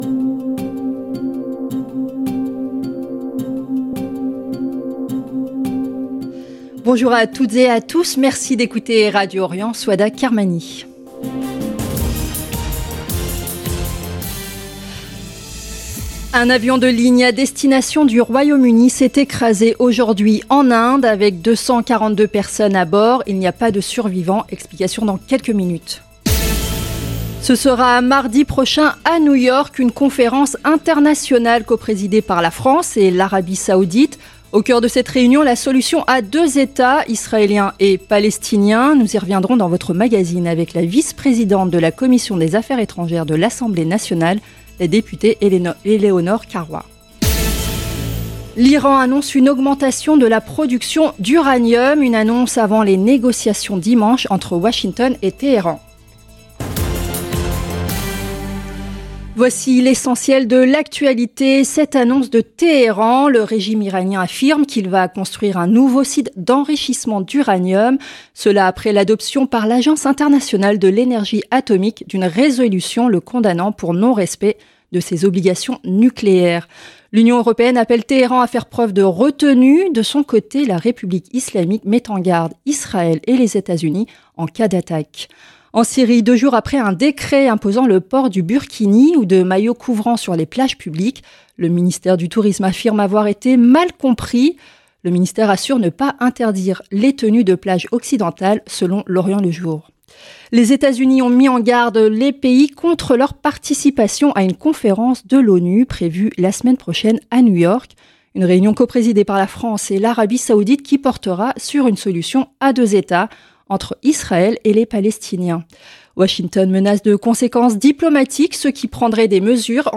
Magazine de l'information de 17H00 du jeudi 12 juin 2025